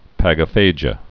(păgə-fājə)